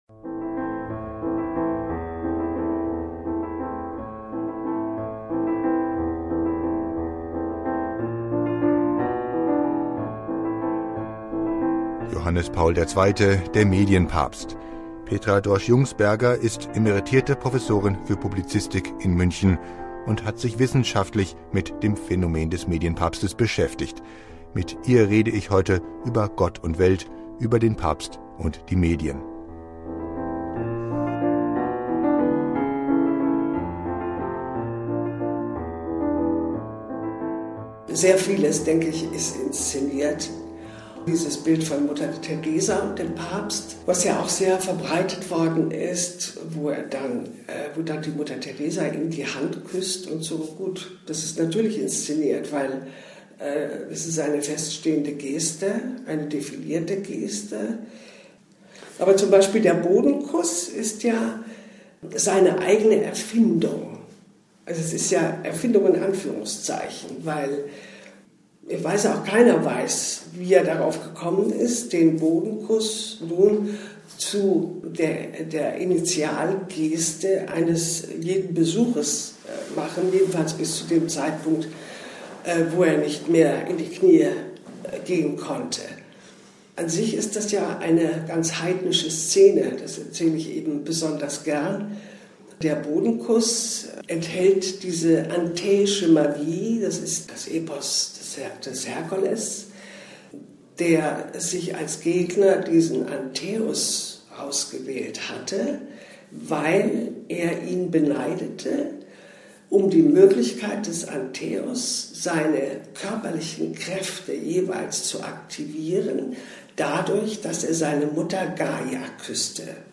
Das Interview haben wir anlässlich der Seligsprechung geführt, zur Heiligsprechung bieten wir noch einmal einen Blick auf „antäische Magie“ und den Wunsch des Papstes, nicht von oben auf die Menschen herab zu sprechen.